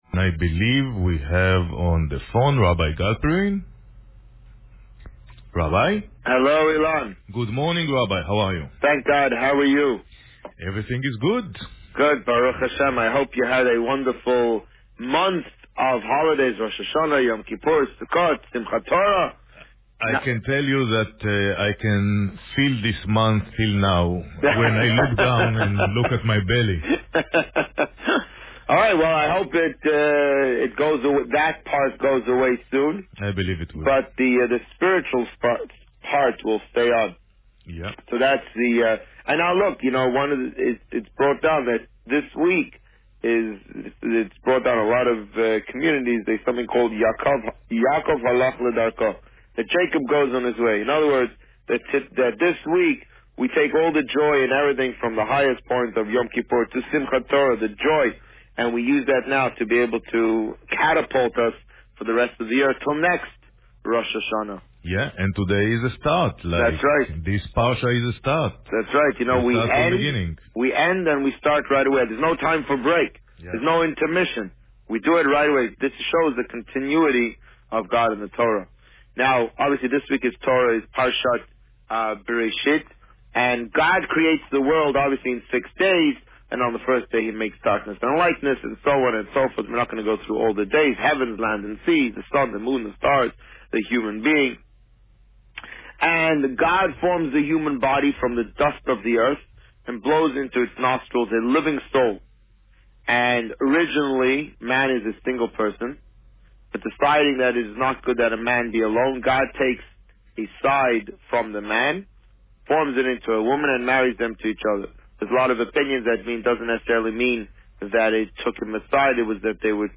The Rabbi on Radio Parsha Bereishit Published: 08 October 2015 | Written by Administrator This week, the Rabbi spoke about the end of the High Holidays and Parsha Bereishit.